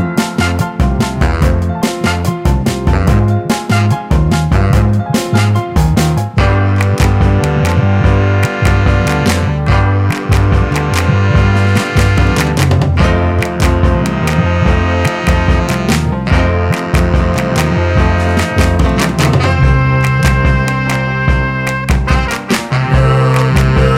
Minus Guitar With Backing Vocals Pop (2000s) 3:37 Buy £1.50